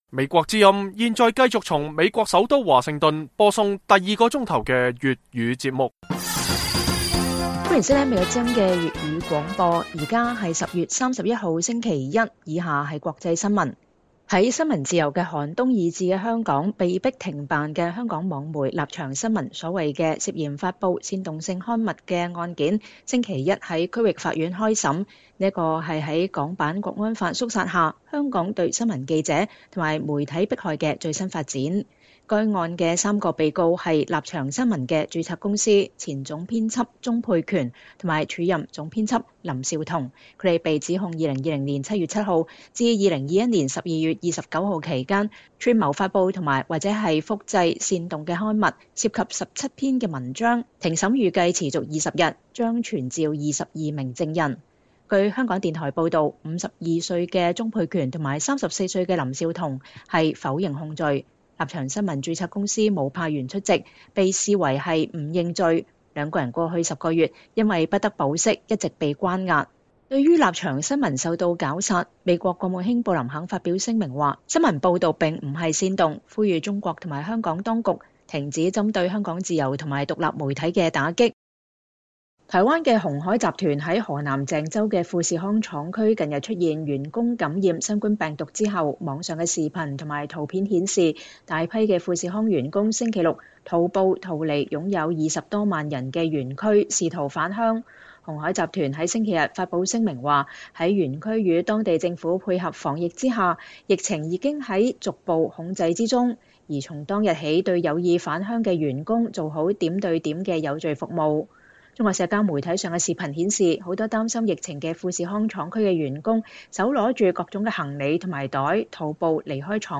粵語新聞 晚上10-11點: 香港新聞自由寒冬下親民主網媒立場新聞“煽動”案開審